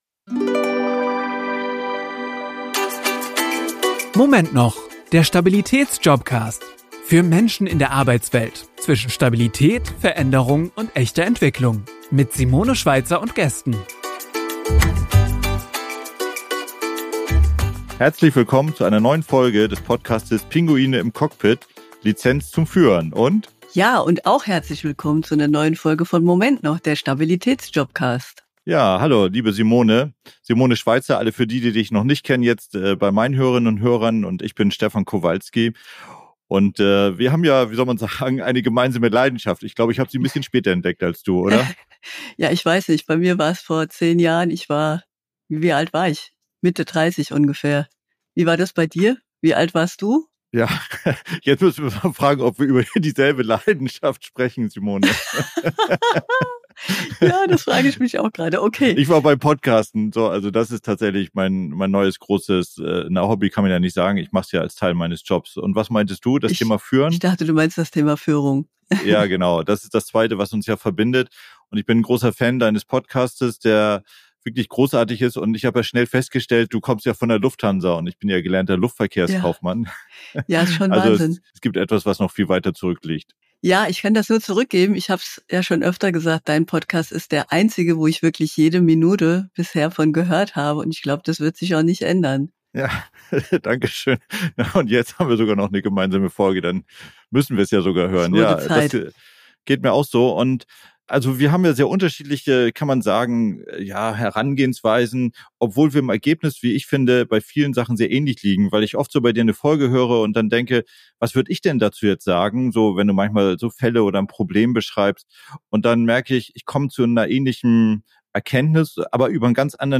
Ein Gespräch über Motivation, Veränderung, Werte – und warum wir am Ende öfter übereinstimmen, als wir selbst erwartet hätten.